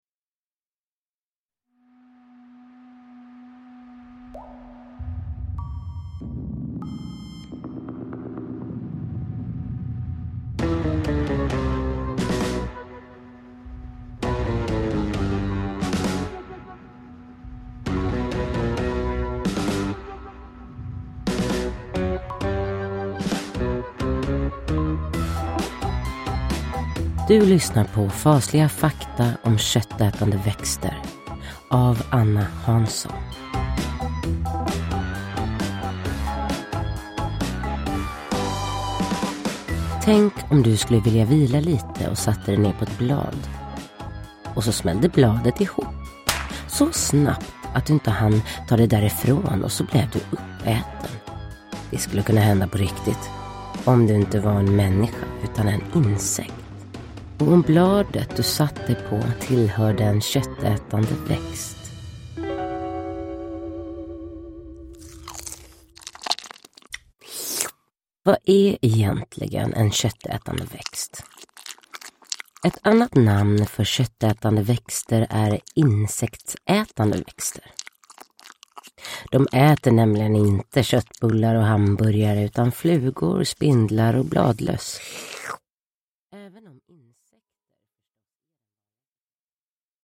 Fasliga fakta om köttätande växter – Ljudbok – Laddas ner